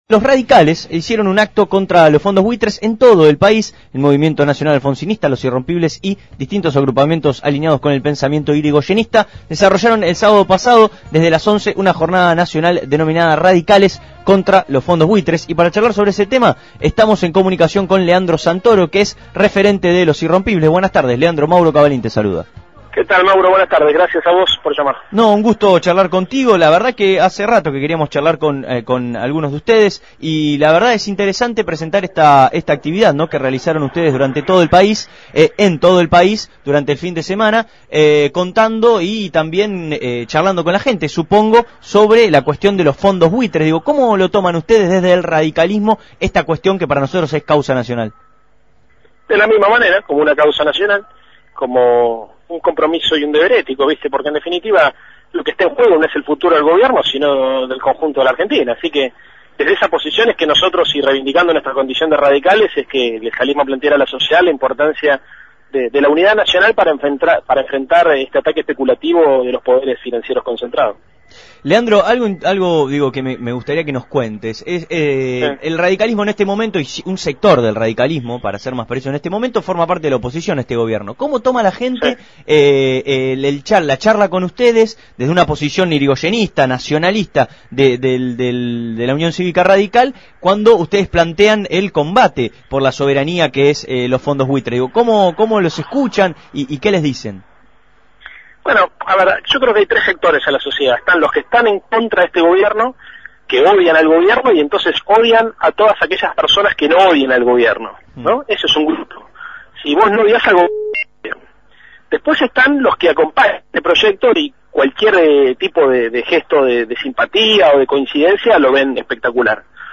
Leandro Santoro, referente de la agrupación radical Los Irrompibles, fue entrevistado en Abramos la Boca, en relación a la jornada de concientización y militancia contra los fondos buitre que realizaron en todo el país conjuntamente con el Movimiento Nacional Alfonsinista.